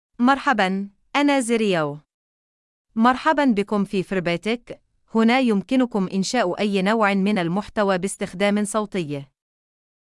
Zariyah — Female Arabic (Saudi Arabia) AI Voice | TTS, Voice Cloning & Video | Verbatik AI
Zariyah is a female AI voice for Arabic (Saudi Arabia).
Voice sample
Listen to Zariyah's female Arabic voice.
Zariyah delivers clear pronunciation with authentic Saudi Arabia Arabic intonation, making your content sound professionally produced.